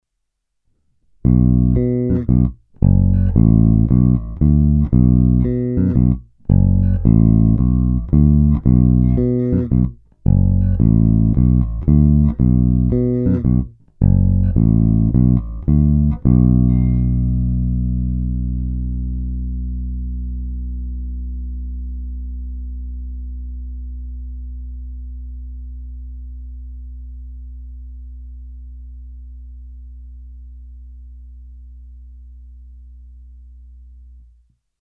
Má sice o něco málo výšek, ale zato o to více basů a také silnější výstupní signál.
Není-li uvedeno jinak, následující nahrávky jsou vyvedeny rovnou do zvukové karty a kromě normalizace ponechány bez zásahů.
Oba snímače sériově